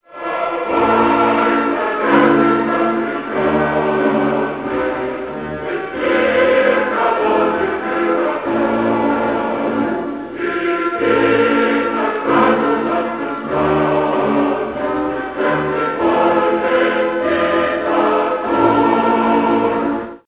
AU file (162 K) - with Red Armada chorus